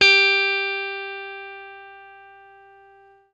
FENDRPLUCKAL.wav